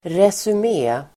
Uttal: [resum'e:]